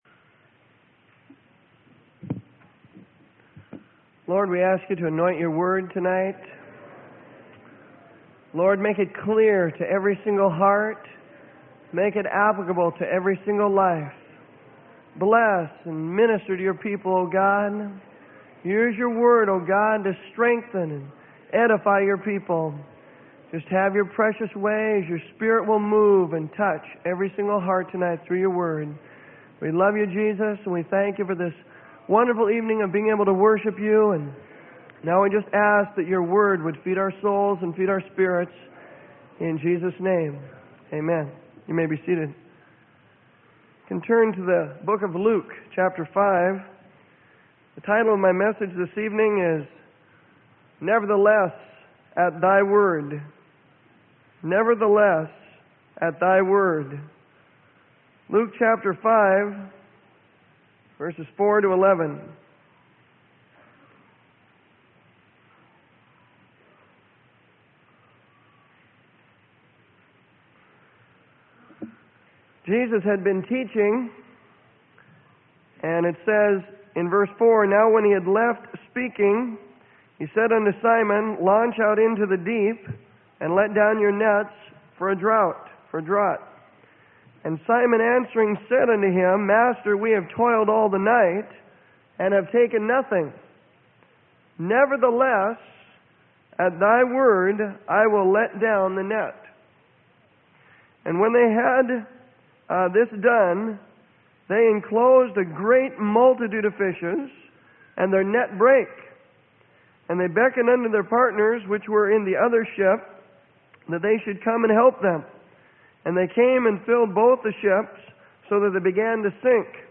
Sermon: Nevertheless, At Thy Word.